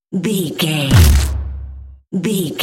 Dramatic hit deep electronic wood
Sound Effects
heavy
intense
dark
aggressive
hits